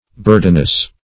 burdenous - definition of burdenous - synonyms, pronunciation, spelling from Free Dictionary Search Result for " burdenous" : The Collaborative International Dictionary of English v.0.48: Burdenous \Bur"den*ous\, a. Burdensome.